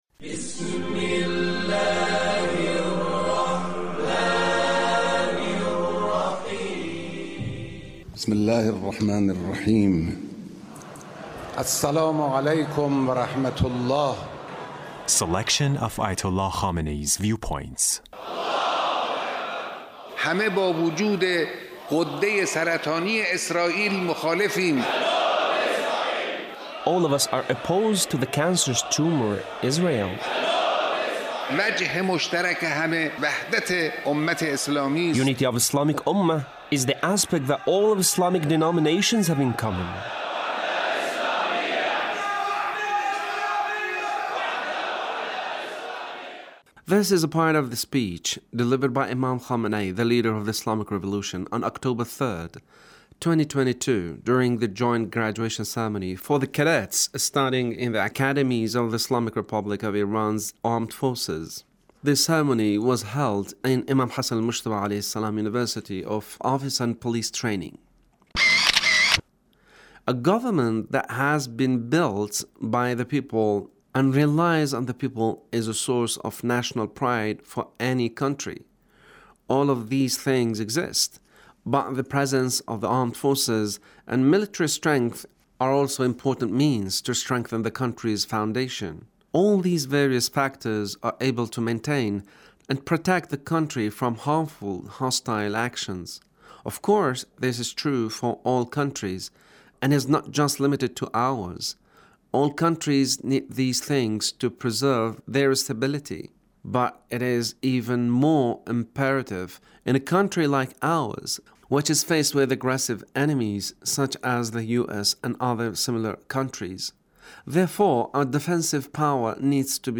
Leader's Speech on Graduation ceremony of Imam Hassan Mojtaba University